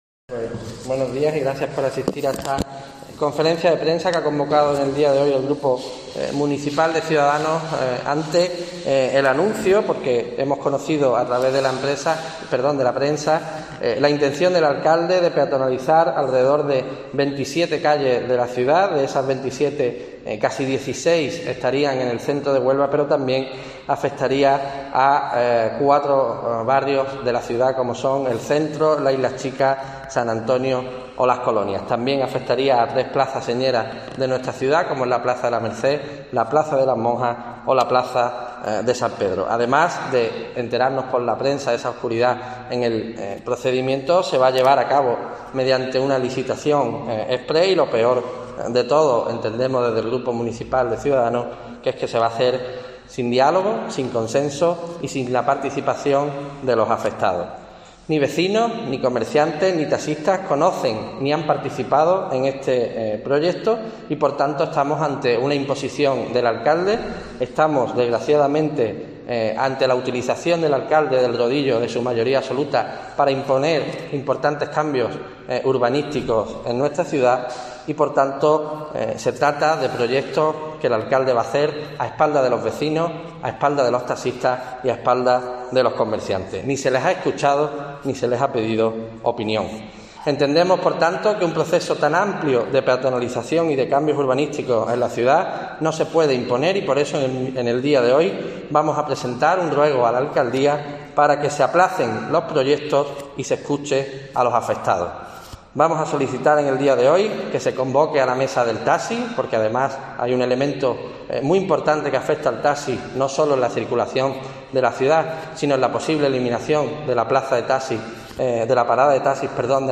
Guillermo García de Longoria, portavoz de Cs en el Ayto Huelva